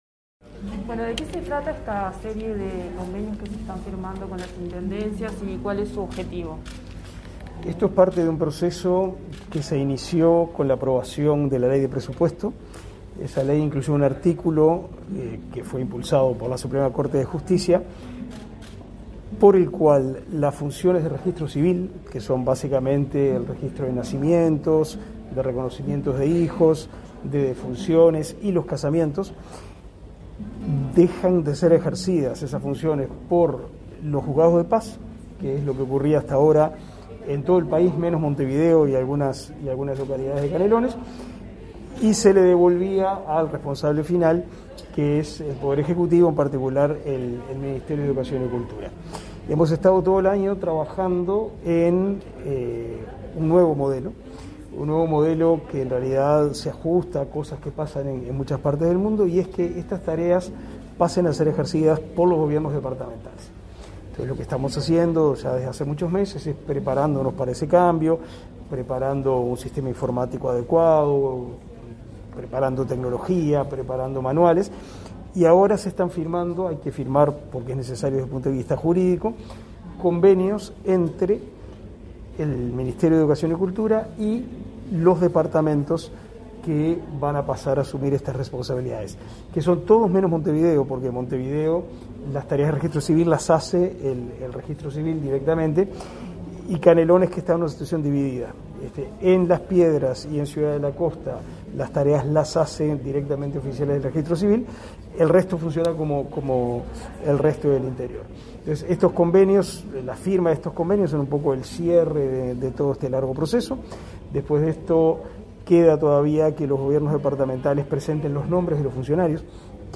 Declaraciones del ministro de Educación y Cultura, Pablo da Silveira
El ministro Pablo da Silveira explicó, a Comunicación Presidencial los alcances de los acuerdos.